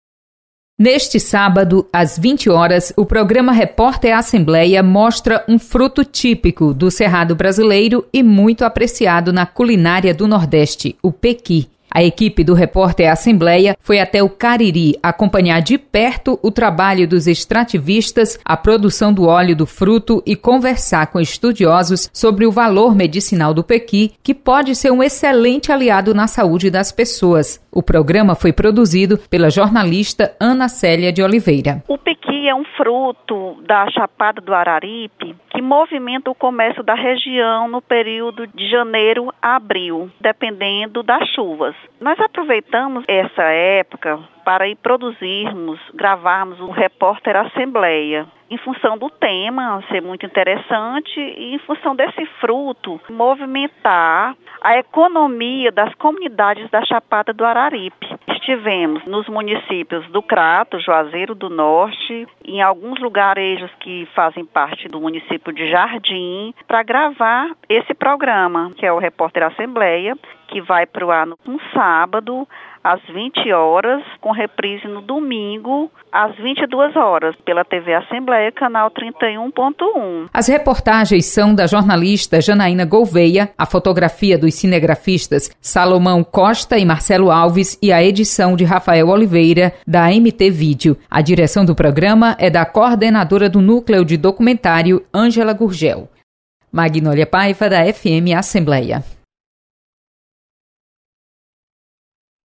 Reportagem da TV Assembleia destaca produção do pequi na Região do Cariri.